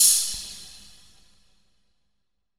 OpenHat_Future.wav